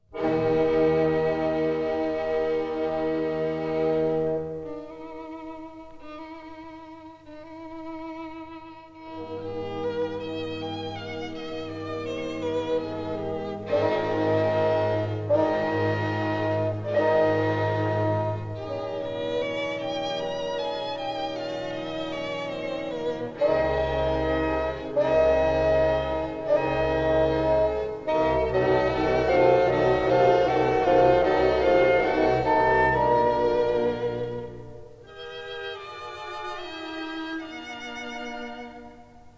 This is a 1959 stereo recording